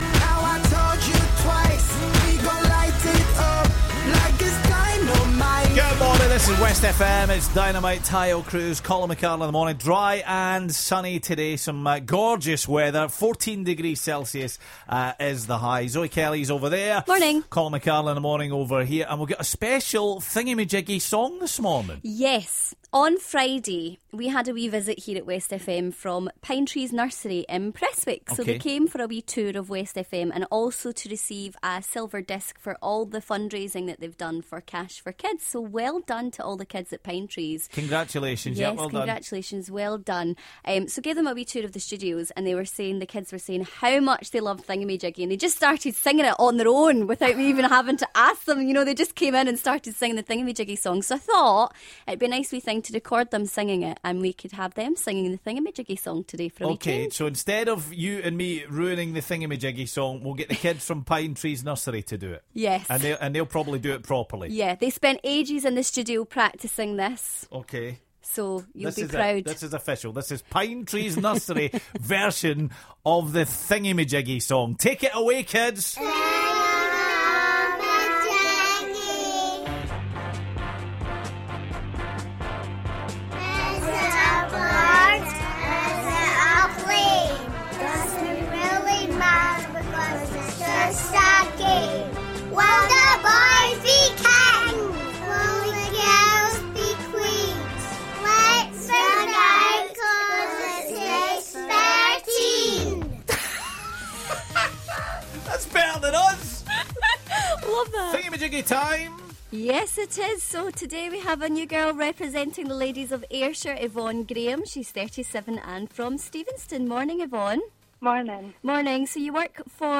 This morning the kids from Pinetrees Nursery in Prestwick took over the Thingummyjiggy song!